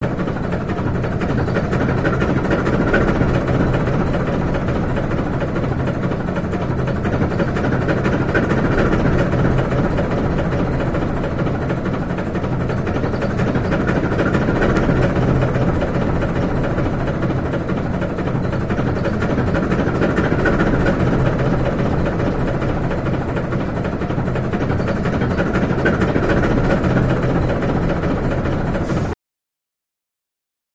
Diesel Locomotive Exterior Idle Loop